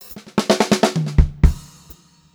128GRFILL1-L.wav